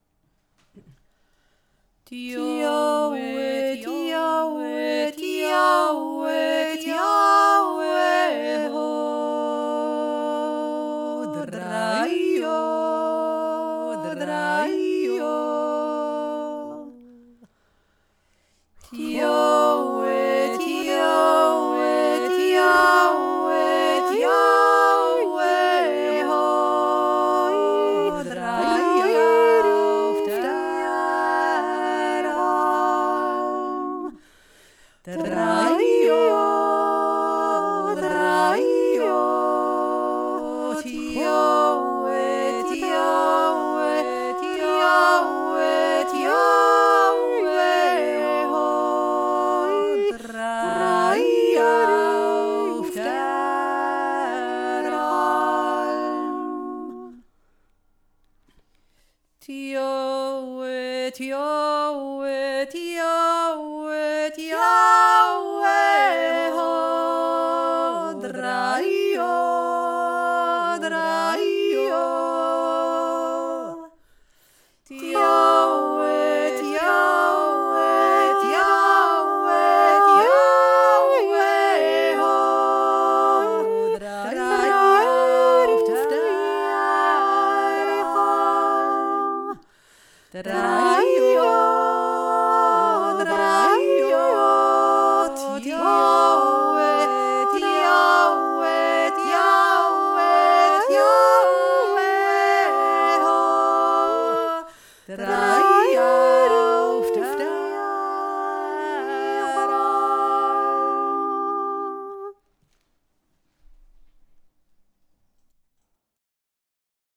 der Jodler zweistimmig
dioe-dioe-dioe-dioe-ho.mp3